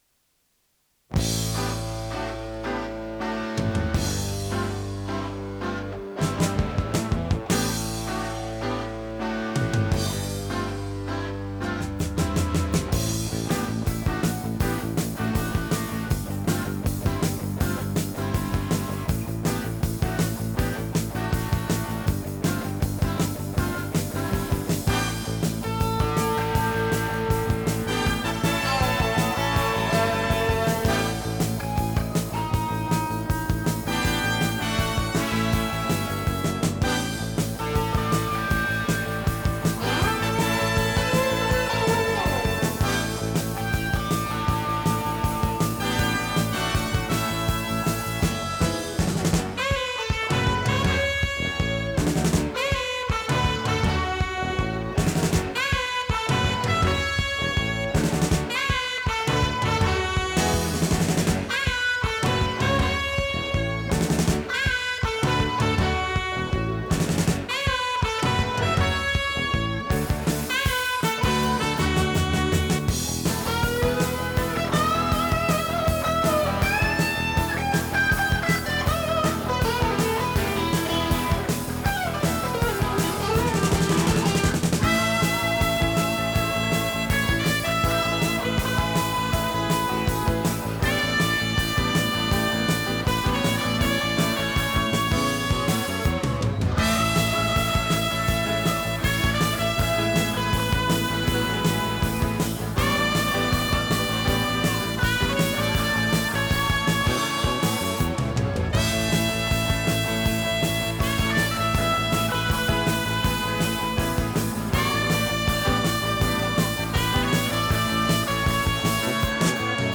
ノイズリダクションOFF
【フュージョン・ロック】★他機で録音したテープ　録音デッキ：TEAC C-3 48kHz-24bit 容量34.5MB